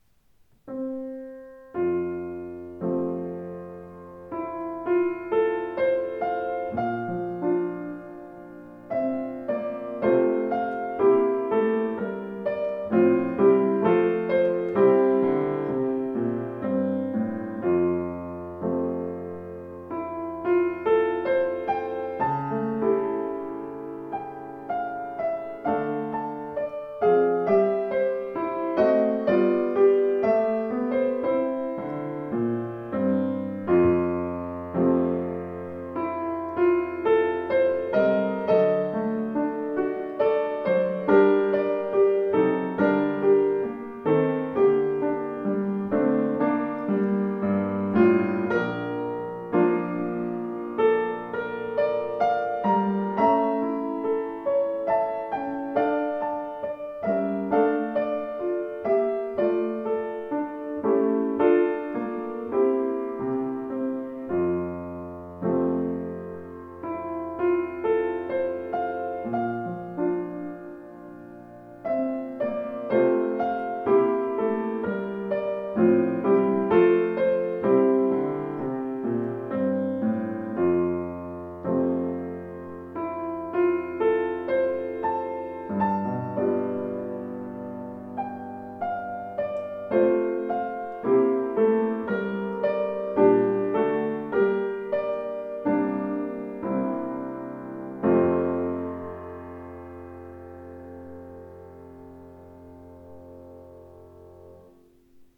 Dämpfungspedal (Moderator), großes Tonvolumen dank neuer Konstruktion mit großzügig ausgelegtem Resonanzboden aus ausgesuchter Bergfichte.
Klaviere